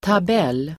Uttal: [tab'el:]